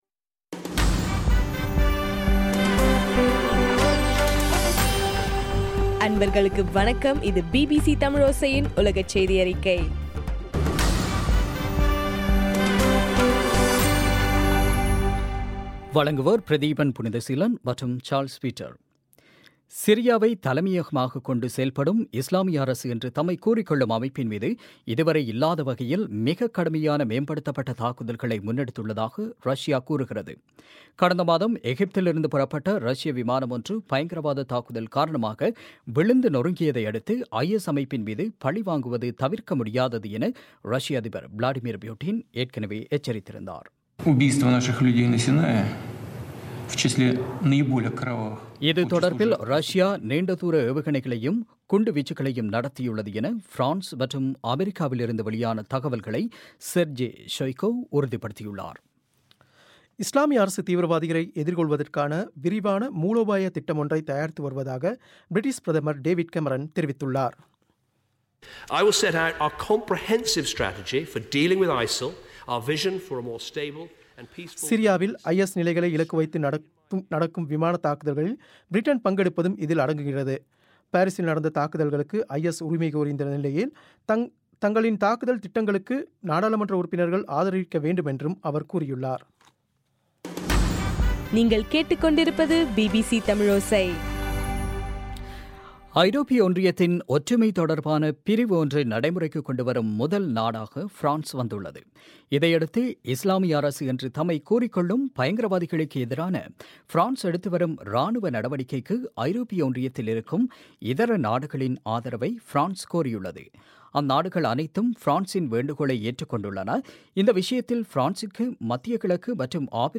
இன்றைய (நவம்பர் 17) பிபிசி தமிழோசை செய்தியறிக்கை